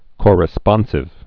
(kôrĭ-spŏnsĭv, kŏr-)